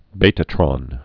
(bātə-trŏn, bē-)